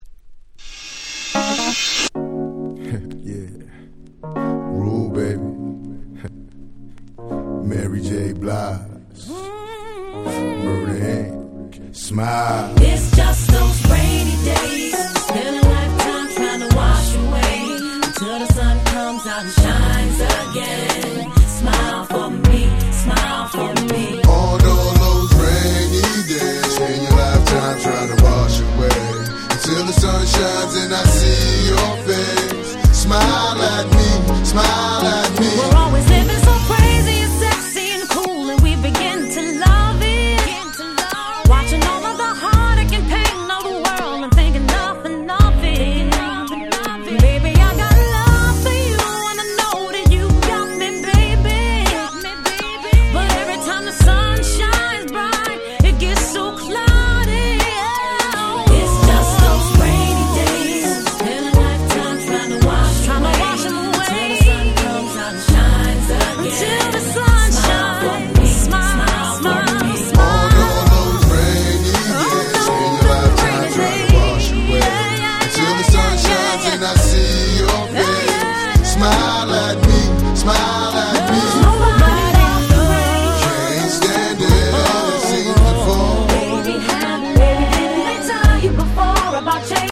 02' Smash Hit R&B !!